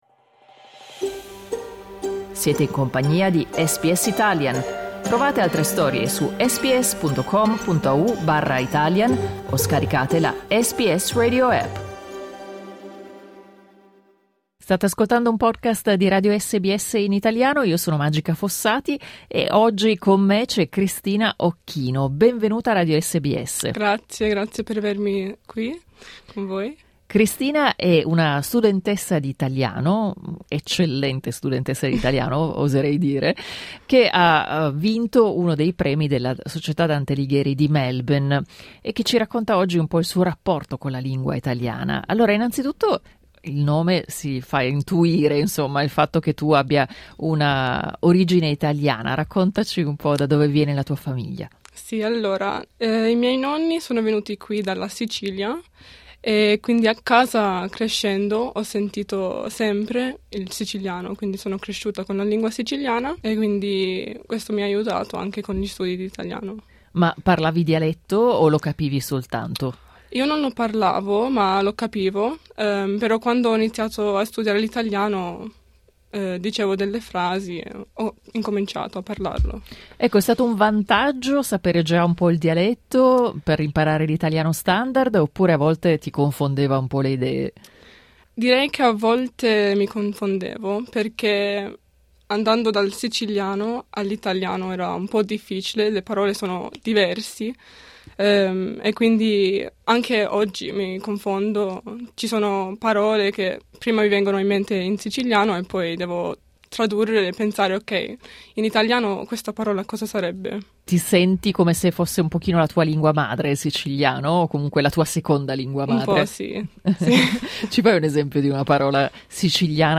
Clicca sul tasto "play" in alto per ascoltare l'intervista integrale